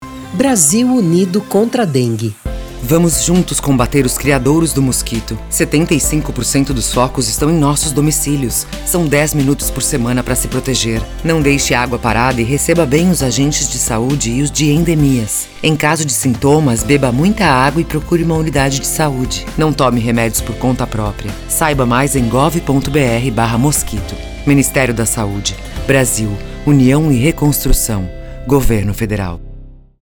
Spot - Brasil unido contra a dengue - Prevenção .mp3 — Ministério da Saúde